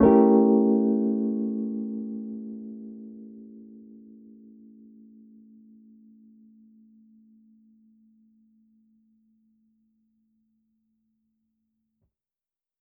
Index of /musicradar/jazz-keys-samples/Chord Hits/Electric Piano 3
JK_ElPiano3_Chord-A7b9.wav